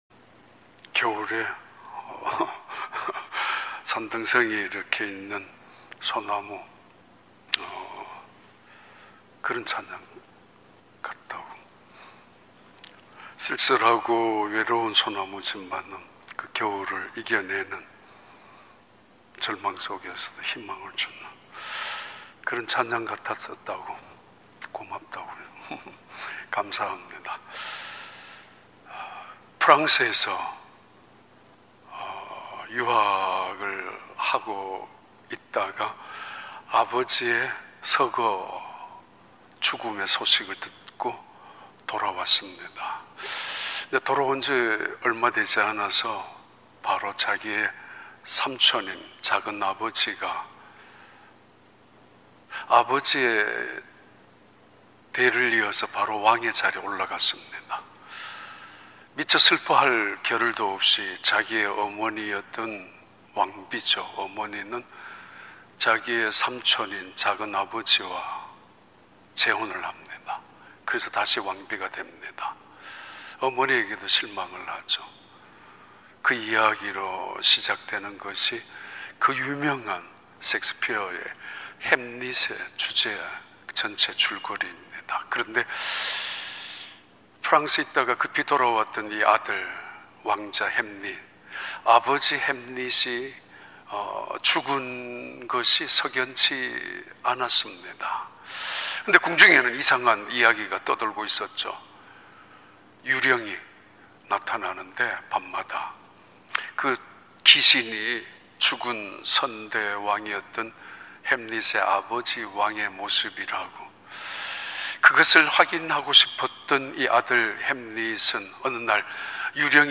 2022년 4월 3일 주일 3부 예배